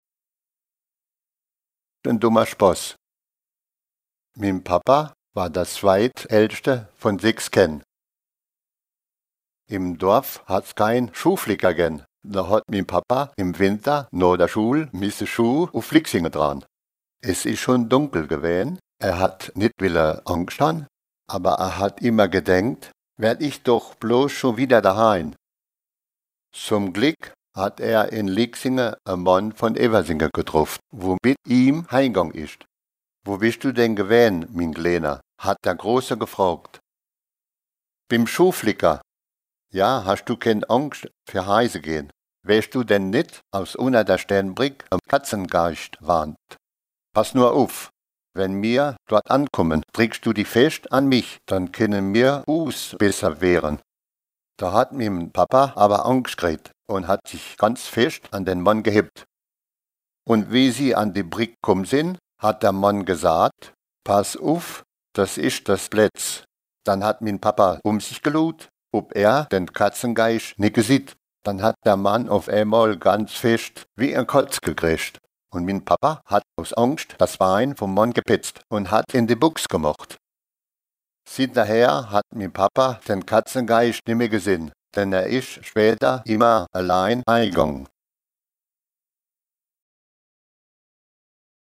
Contes et récits en ditsch enregistrés dans les communes de Linstroff, Grostenquin, Bistroff, Erstroff, Gréning, Freybouse, Petit Tenquin-Encheville, Petit Tenquin et Hellimer-Grostenquin.